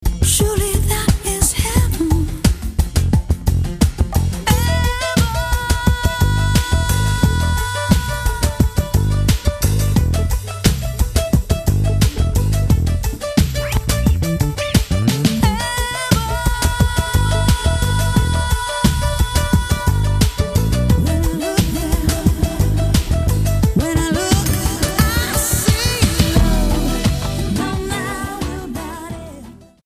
STYLE: Pop
bass and percussion groove